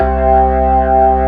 55o-org05-C2.aif